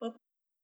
pegchamp/SFX/boop/C.wav at alpha
better sound effects